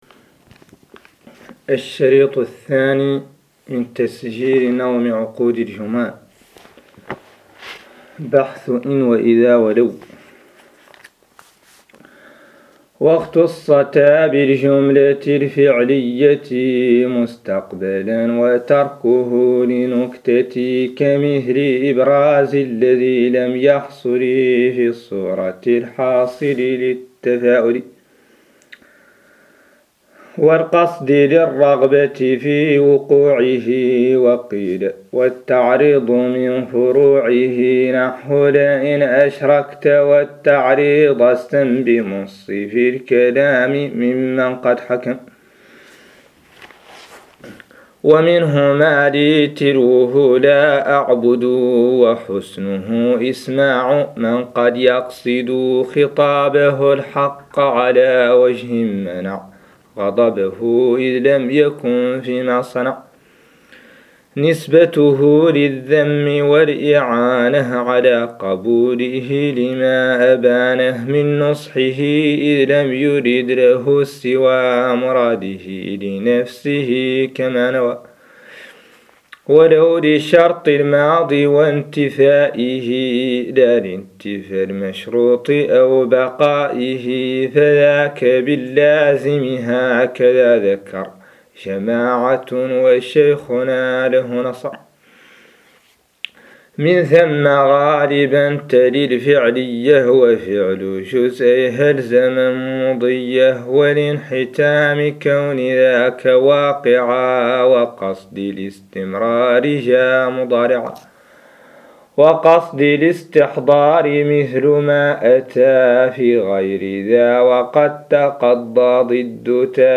قراءة نظم عقود الجمان للسيوطي 02
ouqood-Juman-Reading02.MP3